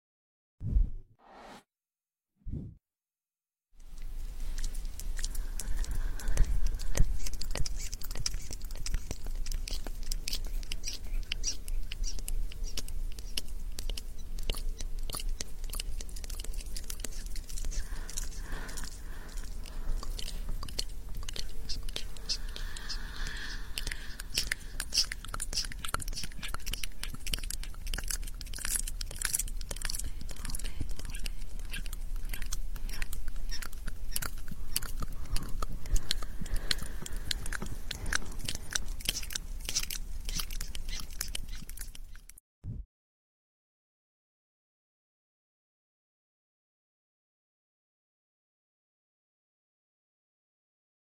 ASMR Visual triggers & Echo sound effects free download